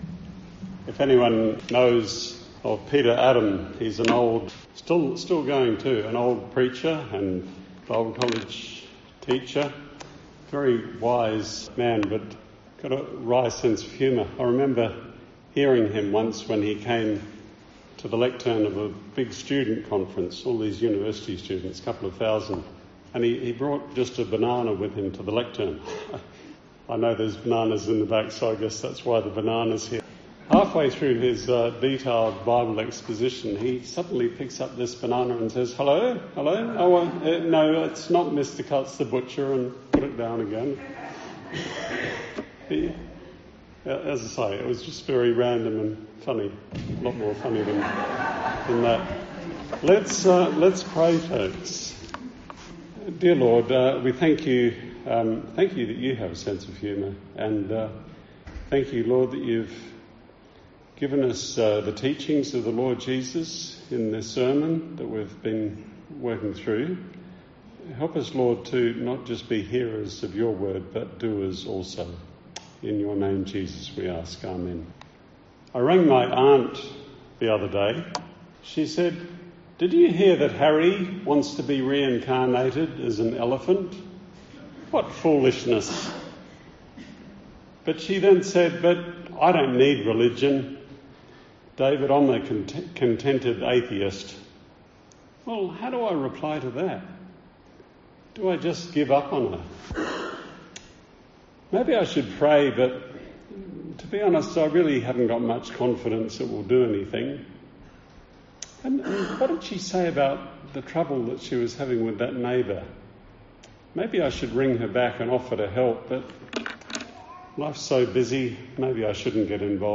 Strathalbyn Church of Christ